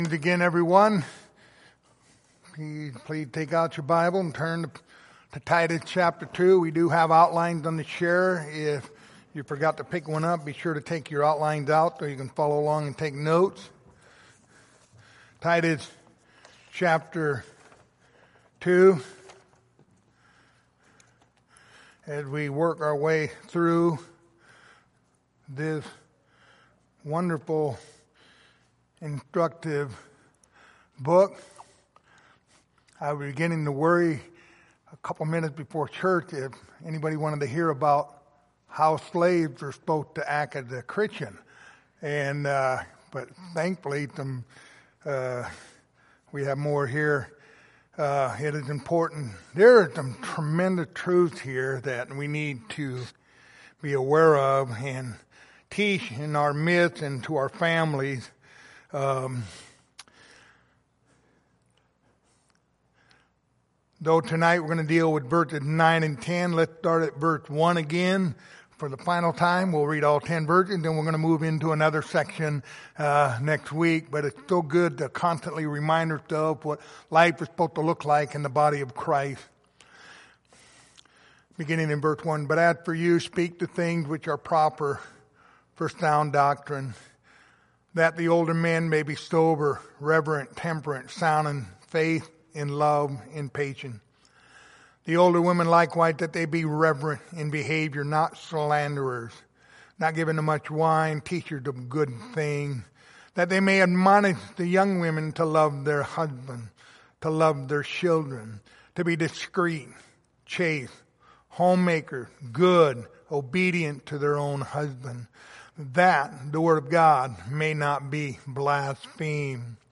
Passage: Titus 2:9-10 Service Type: Sunday Evening Topics